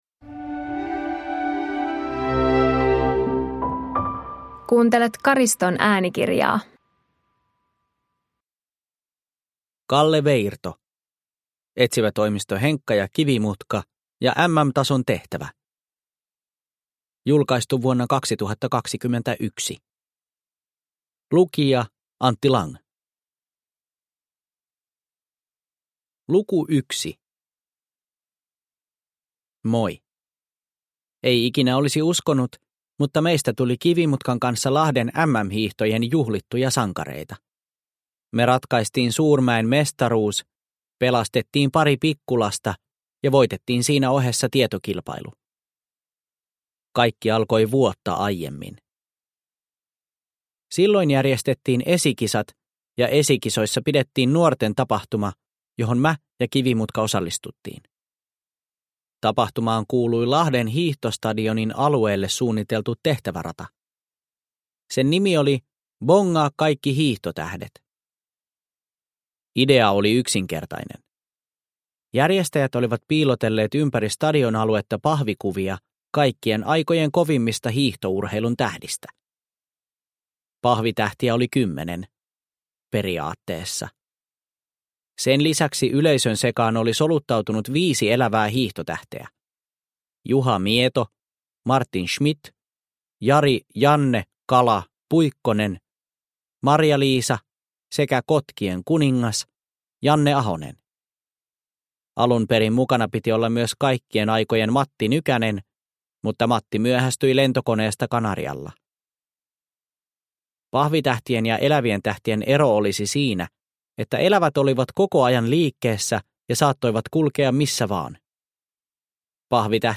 Etsivätoimisto Henkka & Kivimutka ja MM-tason tehtävä – Ljudbok – Laddas ner